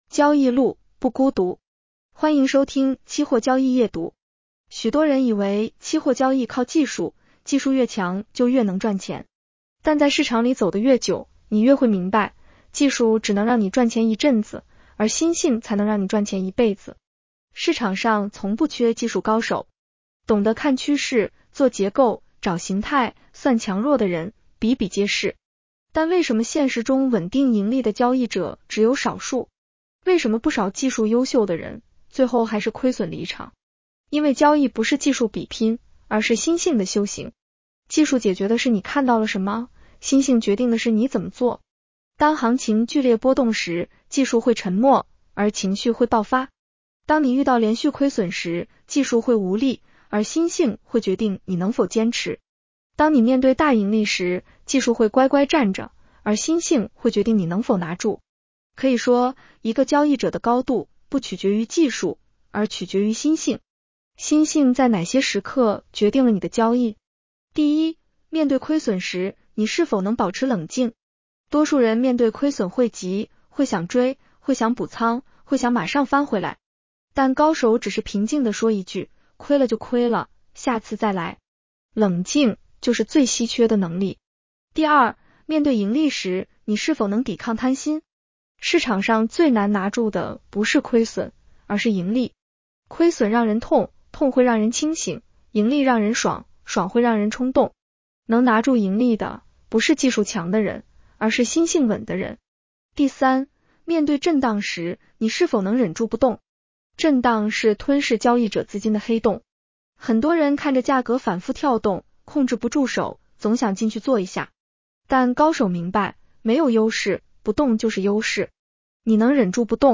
女声普通话版 下载mp3
（AI生成）